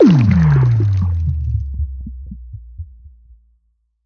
Minecraft Version Minecraft Version latest Latest Release | Latest Snapshot latest / assets / minecraft / sounds / block / conduit / deactivate.ogg Compare With Compare With Latest Release | Latest Snapshot
deactivate.ogg